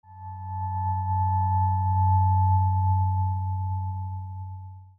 Handcrafted 93.96Hz Weighted Tuning Fork made from high-grade aluminium, providing a long and enduring tone.
93.96Hz Weighted Tuning Fork made from aerospace-grade aluminium.
93.96Hz-Tuning-Fork.mp3